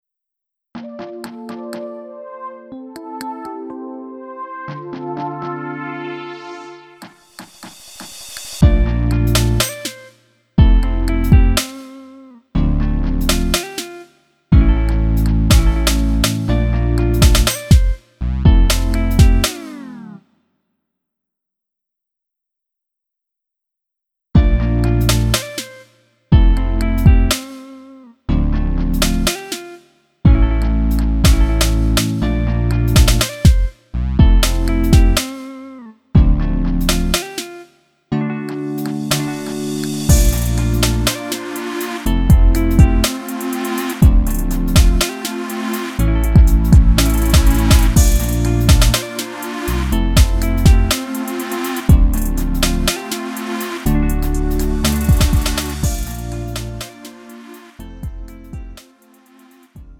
음정 -1키
장르 가요 구분 Lite MR
Lite MR은 저렴한 가격에 간단한 연습이나 취미용으로 활용할 수 있는 가벼운 반주입니다.